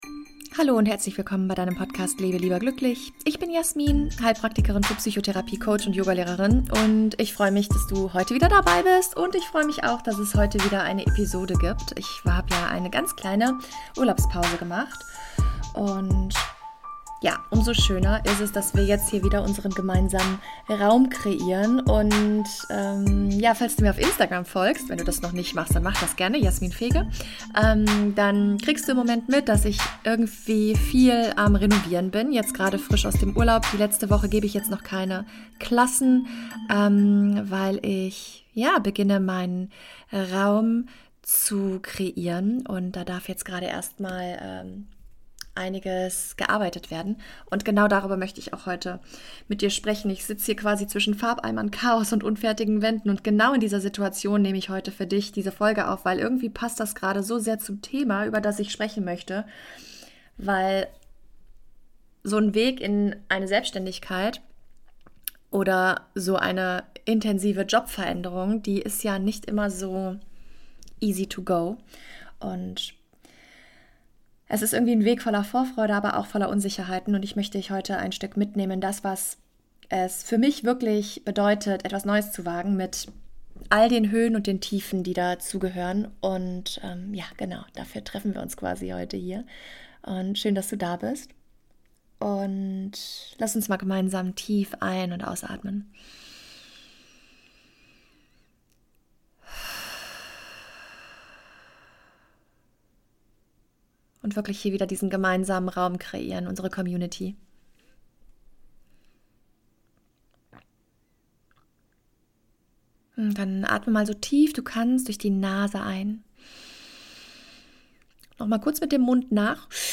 Beschreibung vor 6 Monaten Ich nehme dich heute mit mitten in meine eigene Baustelle – im wahrsten Sinne. Zwischen unfertigen Wänden, Renovierungschaos und Vorfreude auf meine neue Praxis spreche ich über das, was jeder Neuanfang mit sich bringt: Mut und Zweifel.